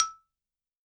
52-prc12-bala-e4.wav